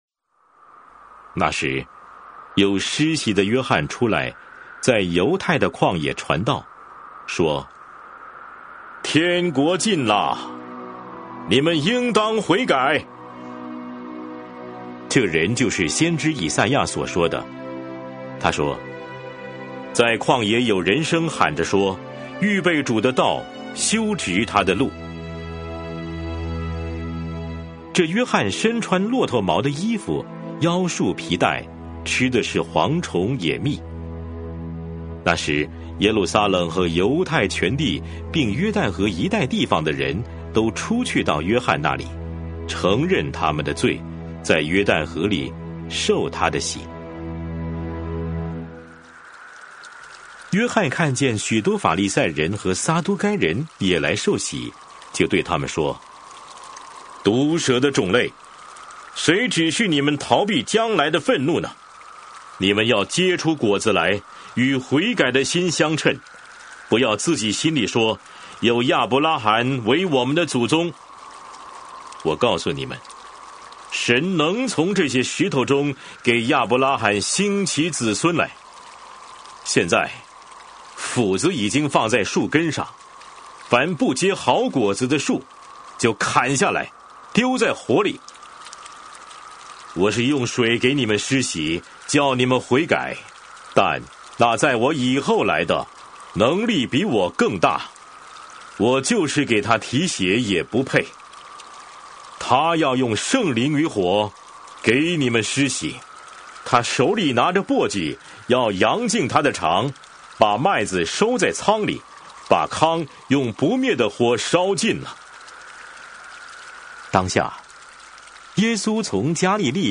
每日读经 | 马太福音3章